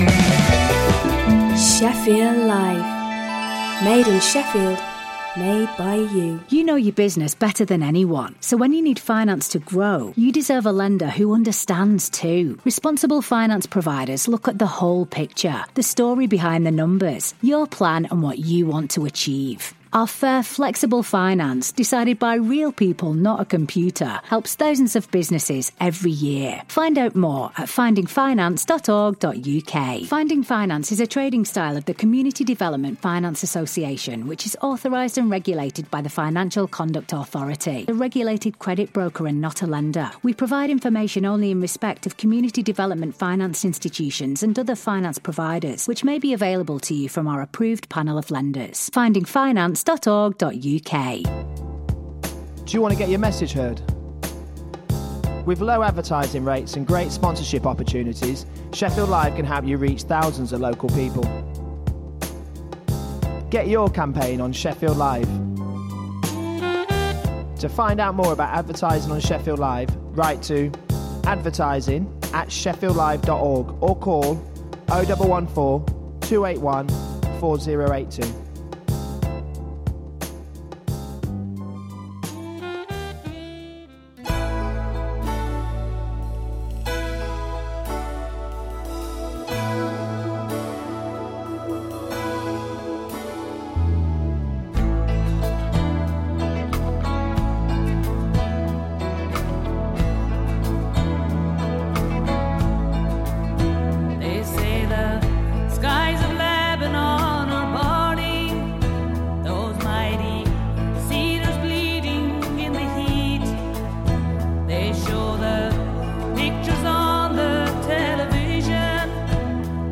Shefffield Live presents…Aaj Ka Sabrang : A mix of different flavours of Asian music from the sub-continent and chat.